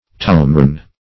tomorn - definition of tomorn - synonyms, pronunciation, spelling from Free Dictionary Search Result for " tomorn" : The Collaborative International Dictionary of English v.0.48: Tomorn \To*morn"\, adv.